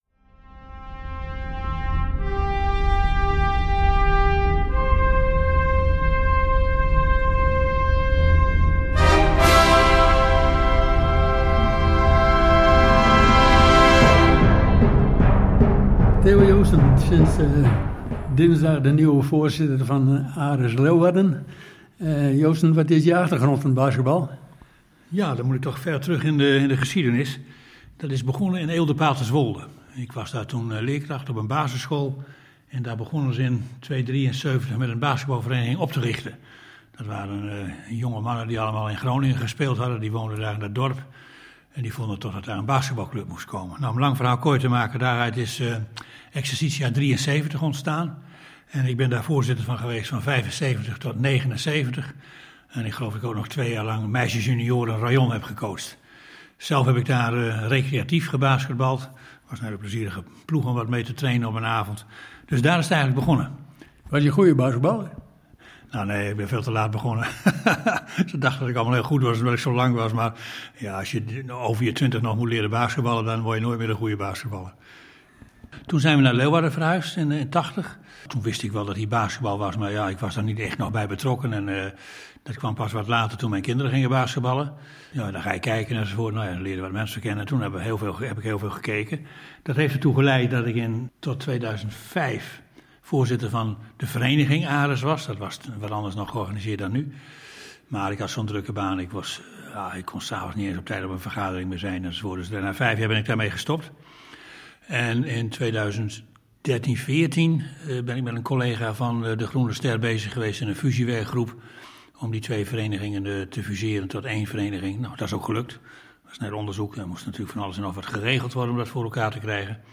Underweis - Interviews - Sport - Onderweg